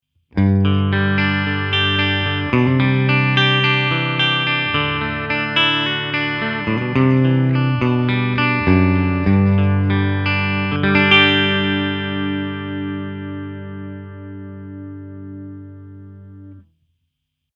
Tutte le clip audio, sono state registrate con testata a Marshall JCM800 sul canale Low e cassa 2×12 equipaggiata con altoparlanti Celestion Creamback, impostata su un suono estremamente clean.
Suono pulito chitarra/amplificatore senza pedali o effetti
Clean-8.mp3